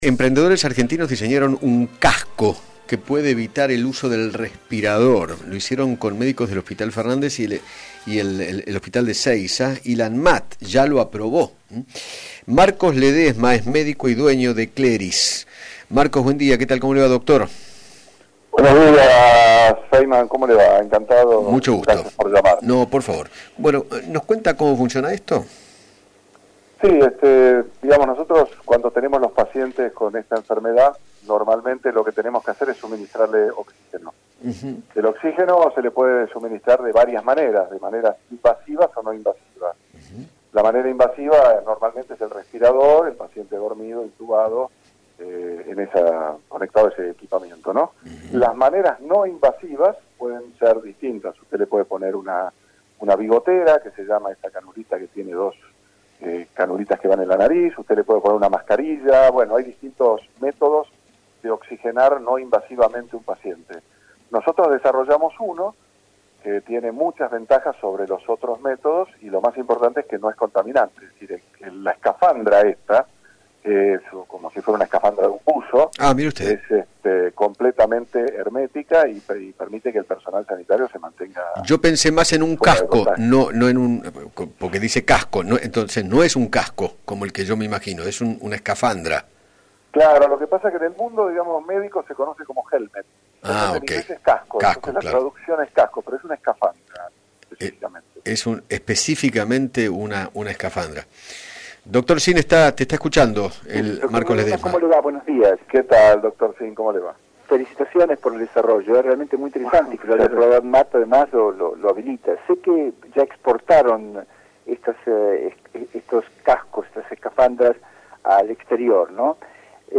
Eduardo Feinmann